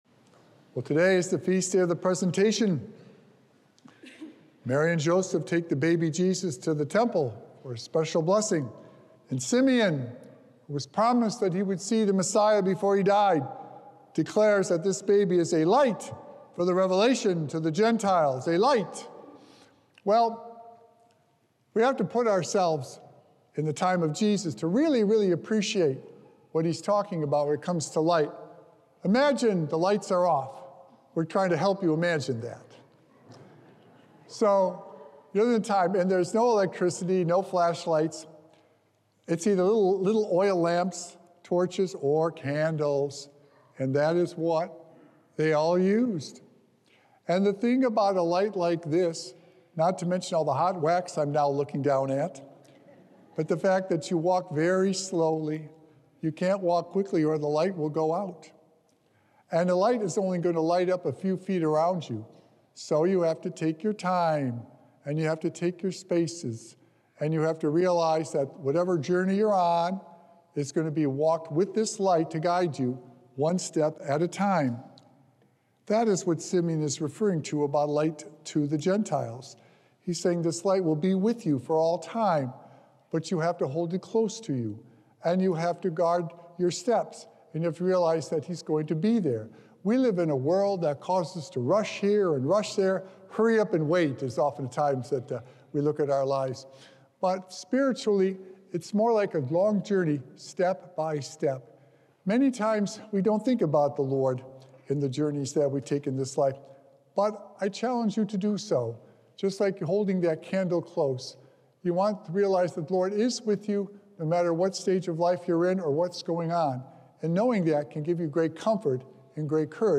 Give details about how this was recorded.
Patience and perseverance in keeping that light near us are important ways to grow! Recorded Live on Sunday, February 2nd, 2024 at St. Malachy Catholic Church.